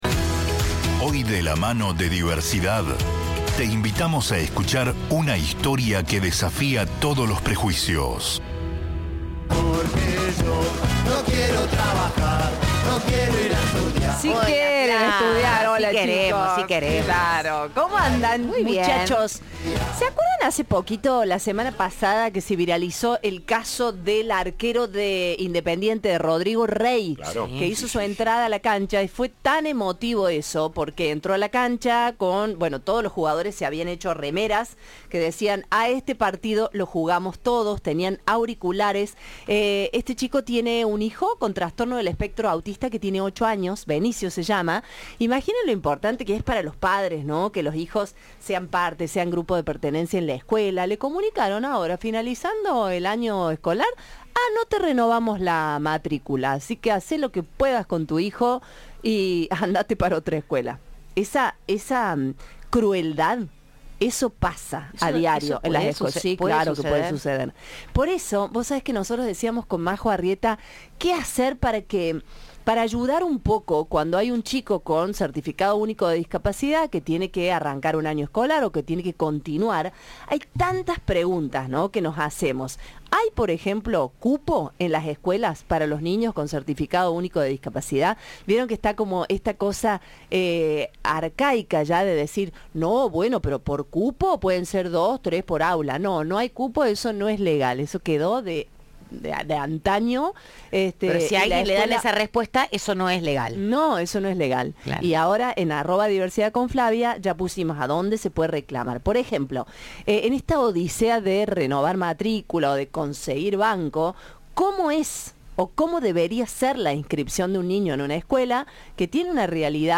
Radio